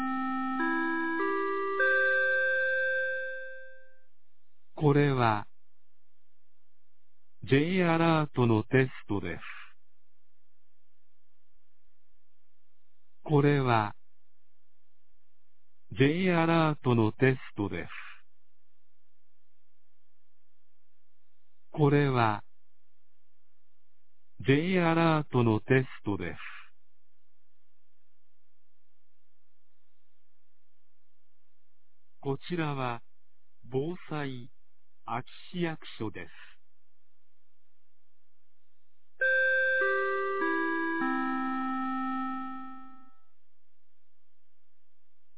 2022年08月10日 11時00分に、安芸市より全地区へ放送がありました。